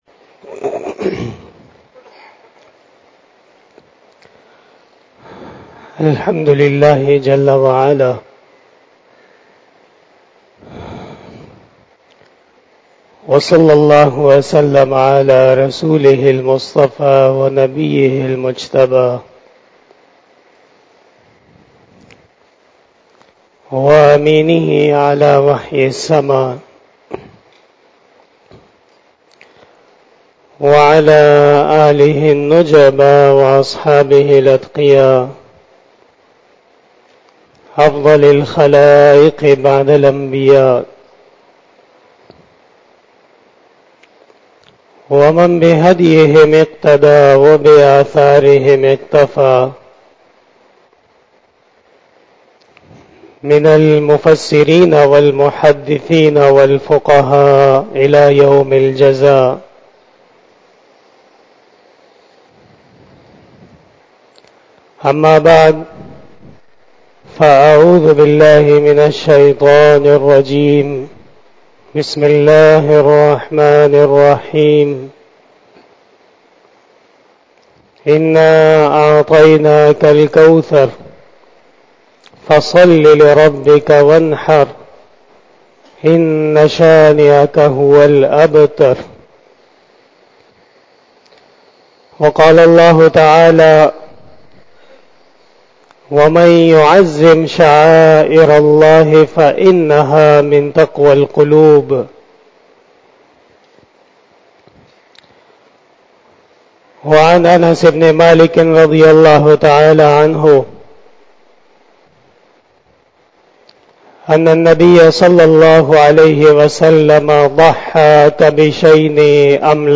Bayan-e-Jummah-tul-Mubarak